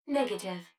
153_Negative.wav